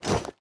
Minecraft Version Minecraft Version 25w18a Latest Release | Latest Snapshot 25w18a / assets / minecraft / sounds / item / armor / equip_diamond4.ogg Compare With Compare With Latest Release | Latest Snapshot
equip_diamond4.ogg